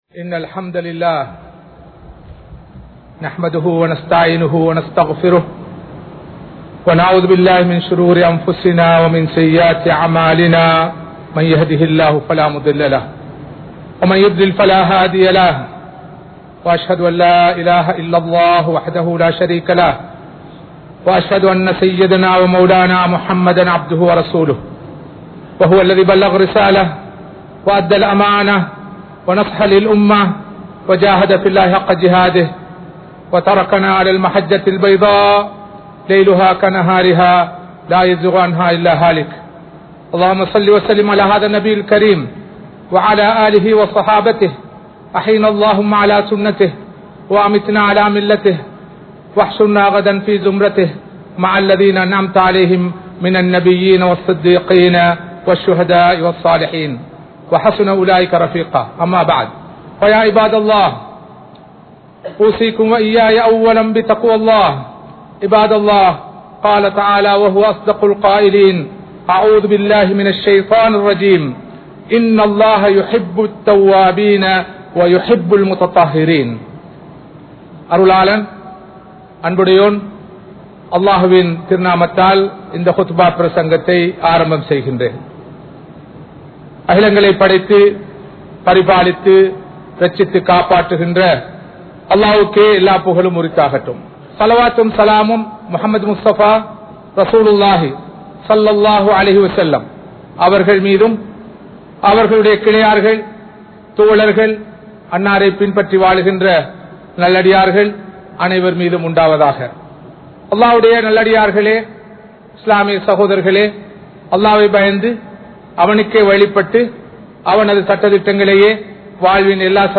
Islamiya Paarvaiel Suththam (இஸ்லாமிய பார்வையில் சுத்தம்) | Audio Bayans | All Ceylon Muslim Youth Community | Addalaichenai
Kollupitty Jumua Masjith